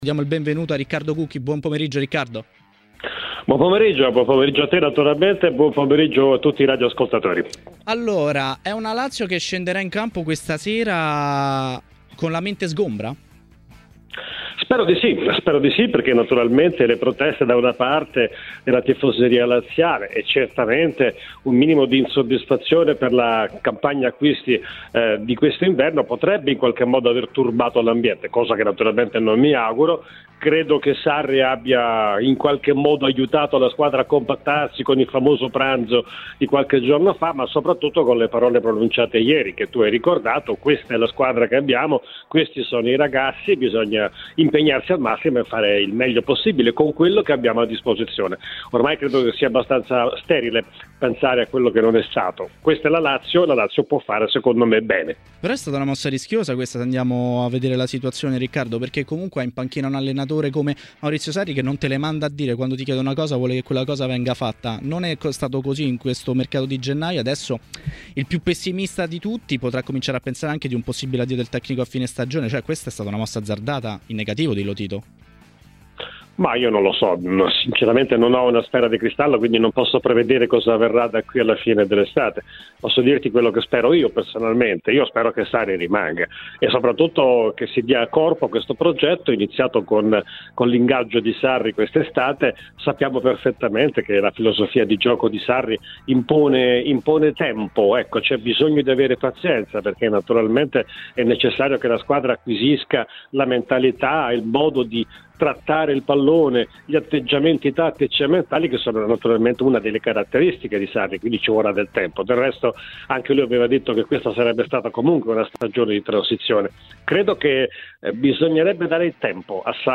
L'ex voce storica di Tutto il calcio minuto per minuto a TMW Radio Riccardo Cucchi, durante Maracanà, si è così espresso sul Milan e la lotta Scudetto: "Credo che questo campionato sia molto divertente.